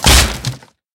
woodbreak.mp3